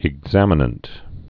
(ĭg-zămə-nənt)